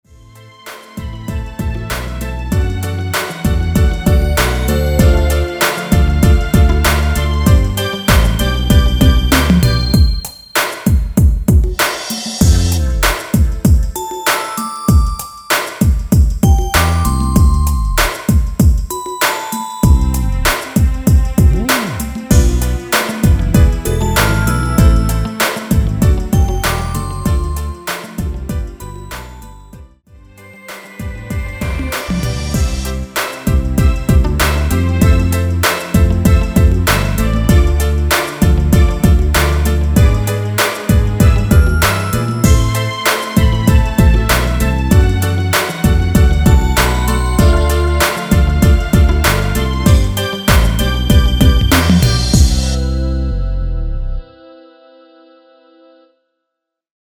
중간 간주 랩 없이 진행되고 마지막 랩 없이 끝납니다.(본문 가사 확인)
원키 랩부분 삭제 편곡한 MR 입니다.(미리듣기 참조)
앞부분30초, 뒷부분30초씩 편집해서 올려 드리고 있습니다.
중간에 음이 끈어지고 다시 나오는 이유는